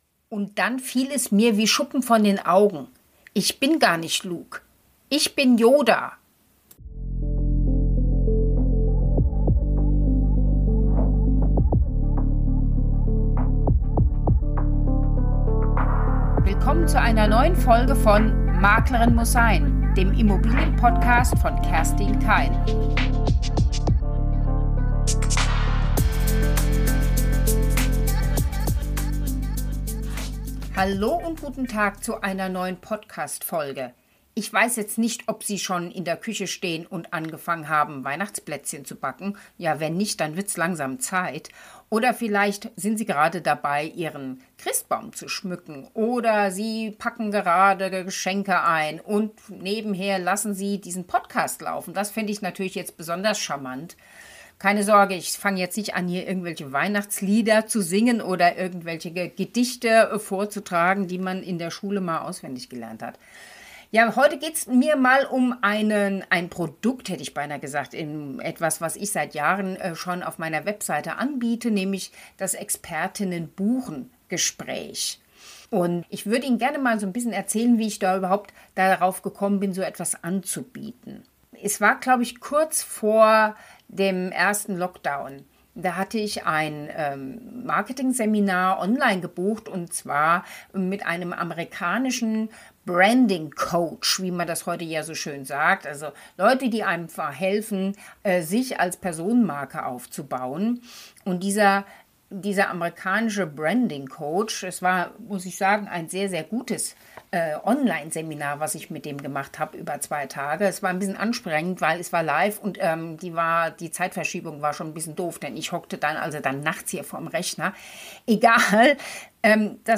Diese Erkenntnis und wie daraus ihr Angebot "Expertin buchen" entstanden ist, erzählt sich in bekanntem Plauderton.